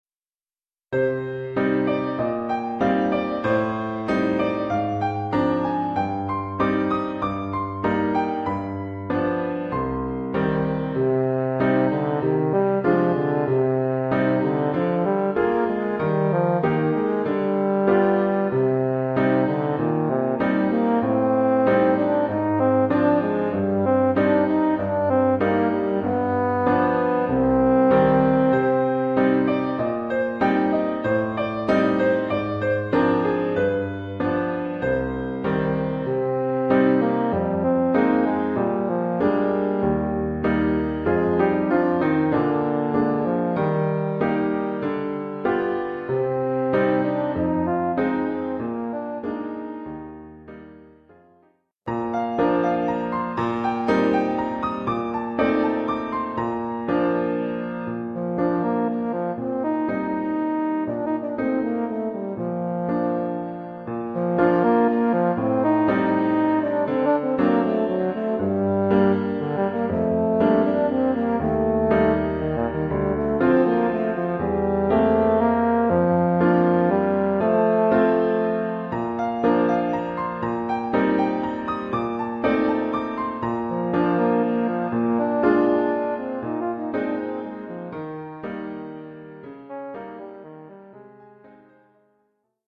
Oeuvre pour saxhorn basse et piano.